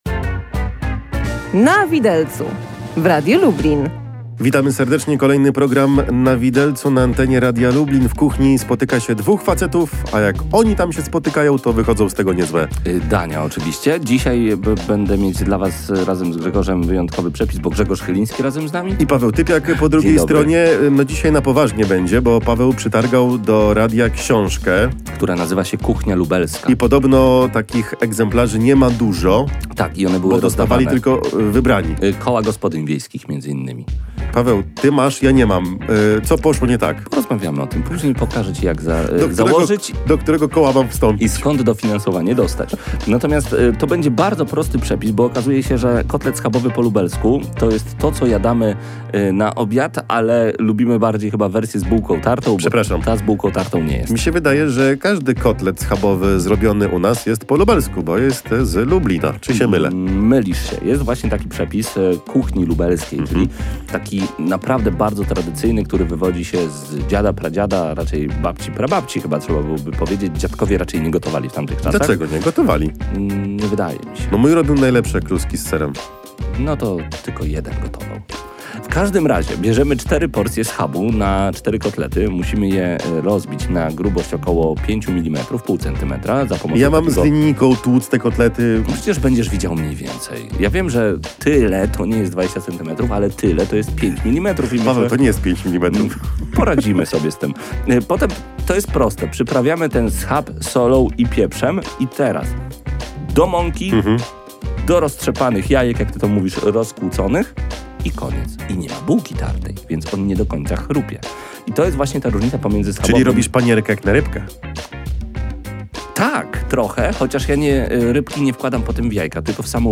rozmawiają dzisiaj o kuchni lubelskiej, a dokładnie o tym, jak przygotować schabowego po lubelsku.